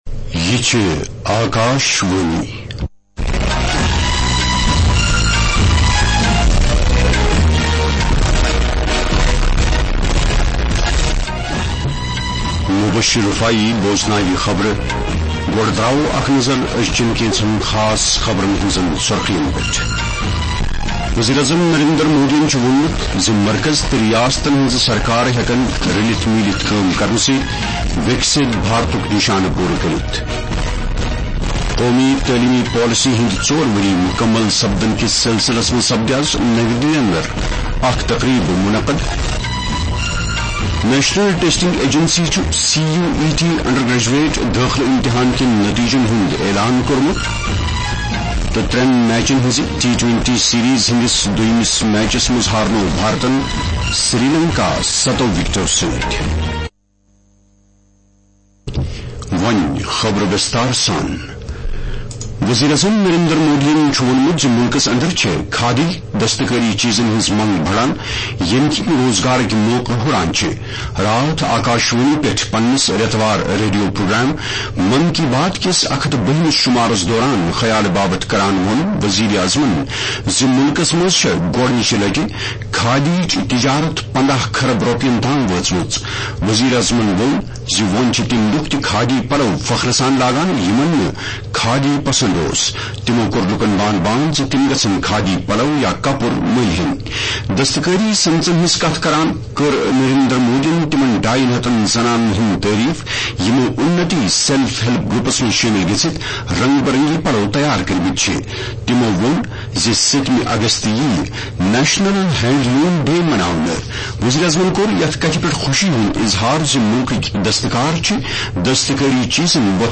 Transcript summary Play Audio Evening News